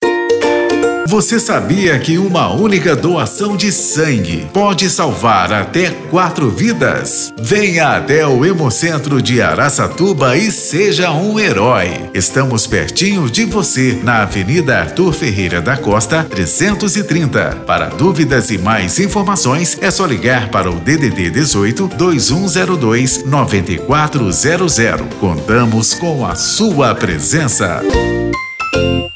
Você também pode ajudar a divulgar a doação de sangue usando nossos spots para rádio ou carros de som: